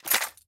ui_interface_116.wav